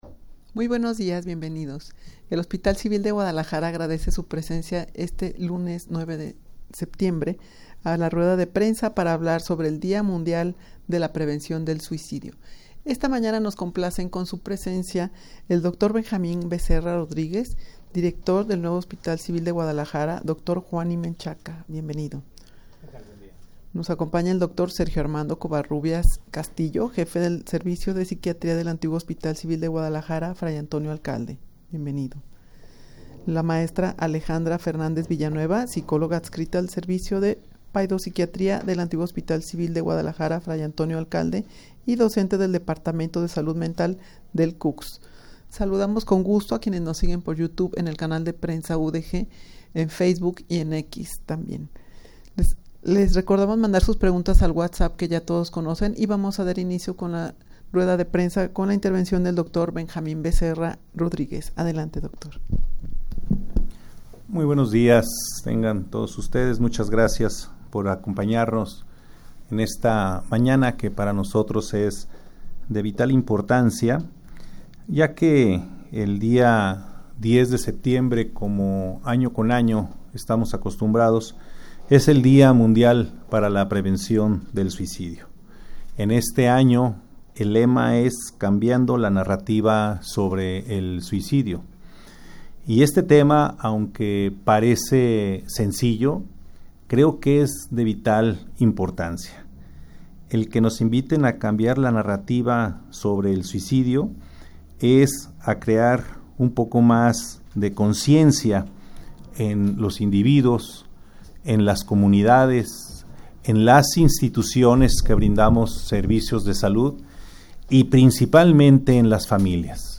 Audio de la Rueda de Prensa
rueda-de-prensa-para-hablar-sobre-el-dia-mundial-para-la-prevencion-del-suicidio.mp3